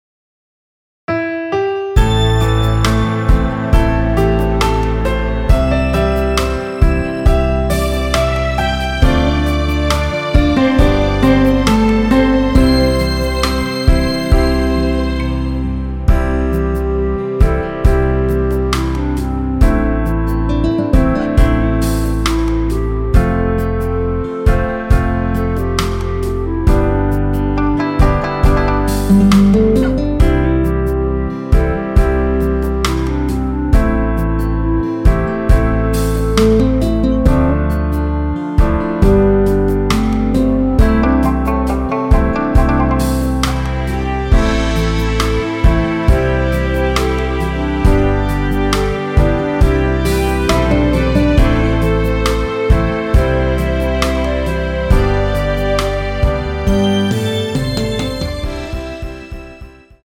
멜로디 MR입니다.
원키에서(-3)내린 멜로디 포함된 MR입니다.
앞부분30초, 뒷부분30초씩 편집해서 올려 드리고 있습니다.
중간에 음이 끈어지고 다시 나오는 이유는